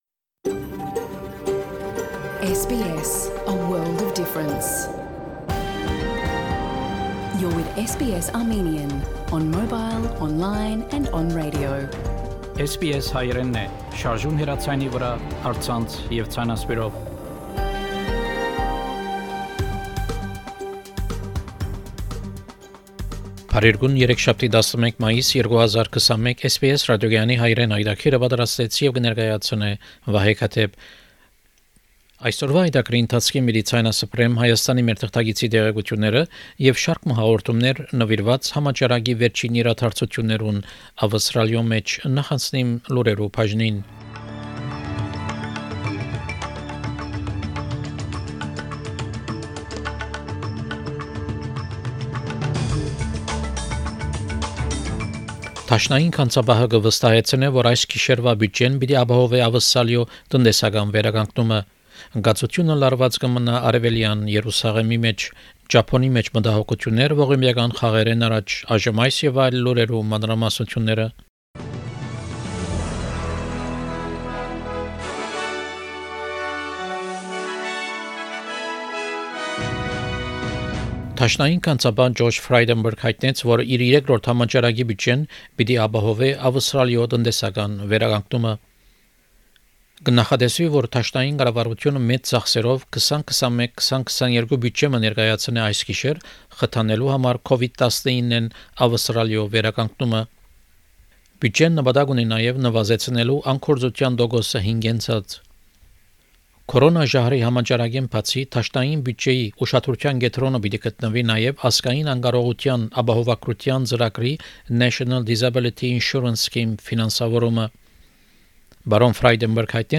SBS Armenian news bulletin – 11 May 2021
SBS Armenian news bulletin from 11 May 2021 program.